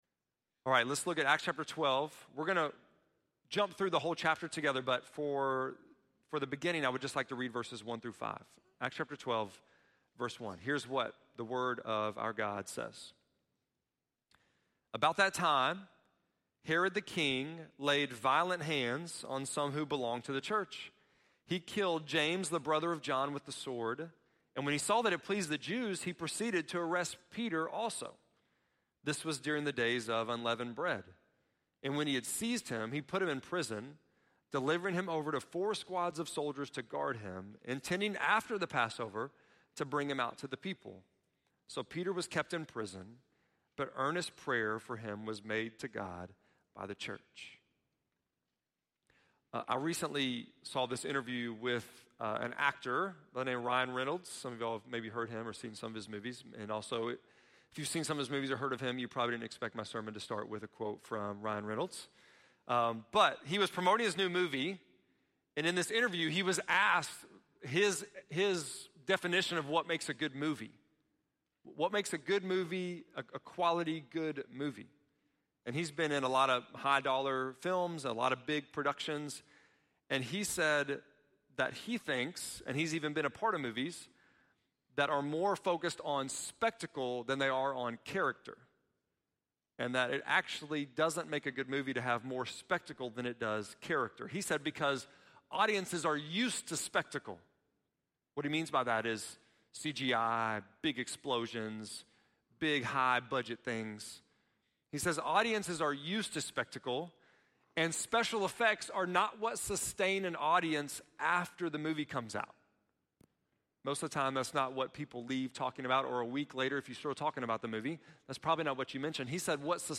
8.25-sermon.mp3